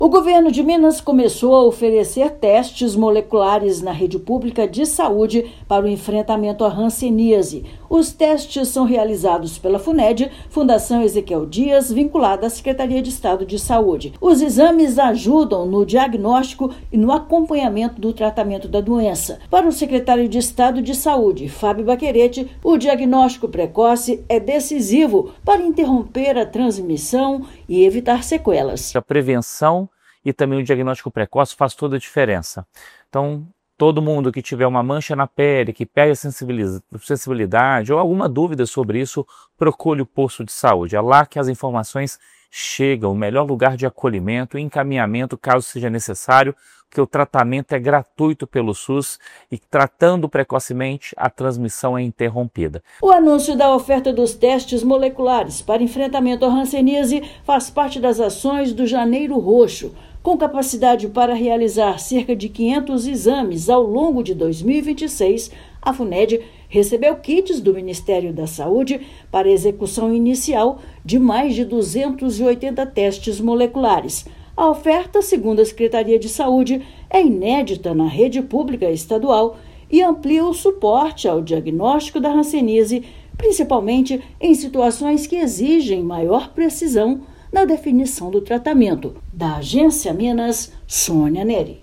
Exames realizados pela Funed ampliam o diagnóstico precoce, reduzem o tempo de resposta e reforçam o cuidado em todo o estado. Ouça matéria de rádio.